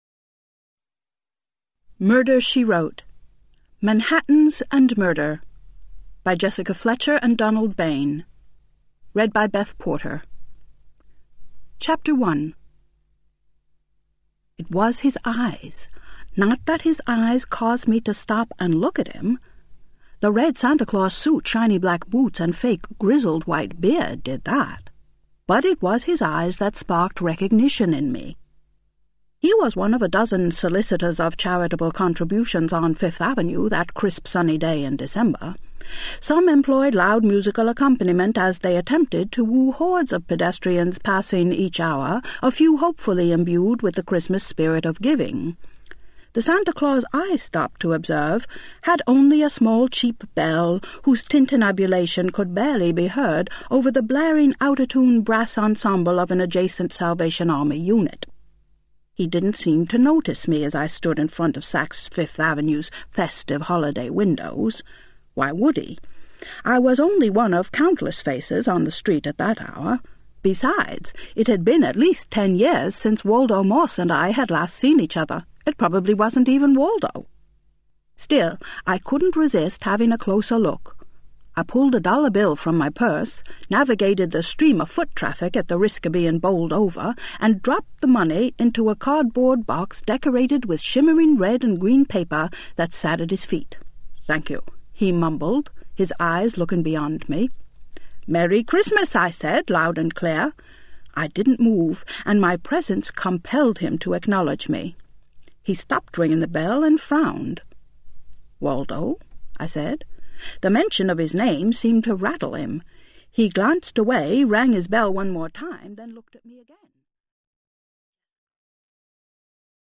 digital digital digital stereo audio file